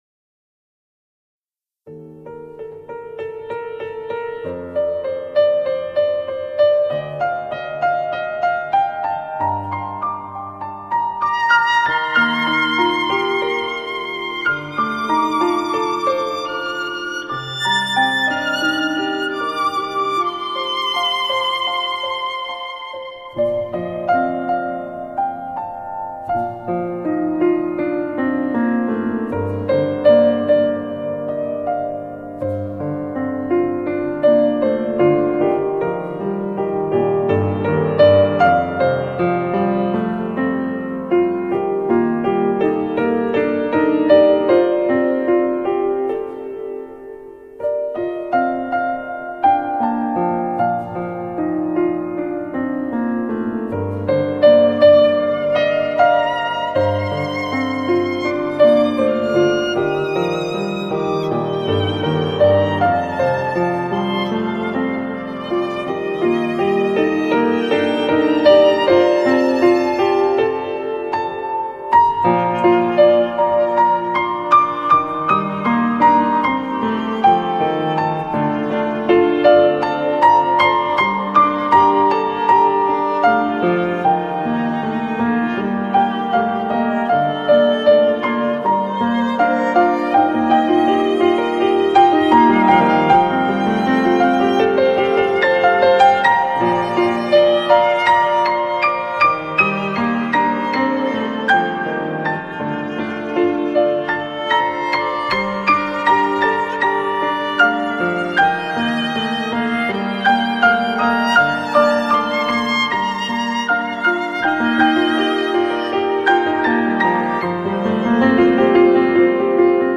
浪漫新世纪音乐
钢琴
小提琴
大提琴
长笛
双簧管
本专辑使用MS录音技术，力求钢琴的饱满与音场，大提琴也特别针对演奏技巧采用多点收音手法呈现。